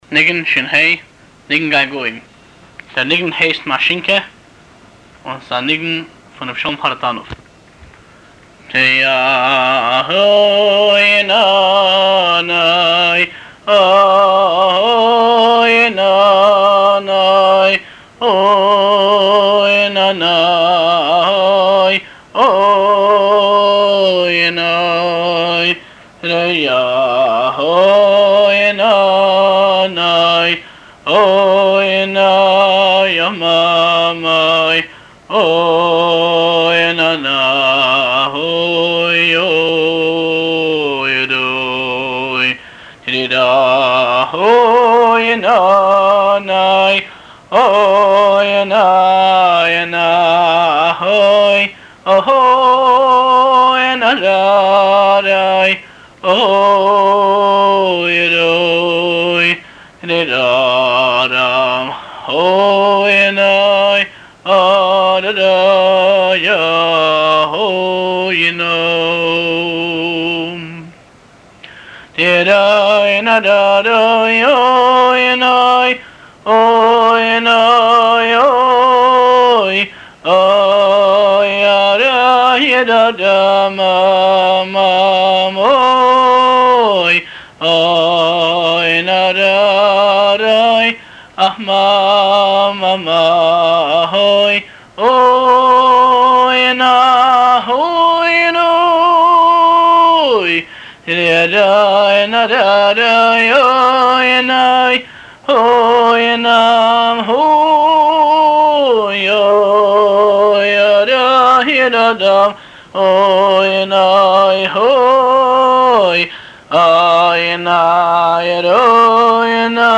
הבעל-מנגן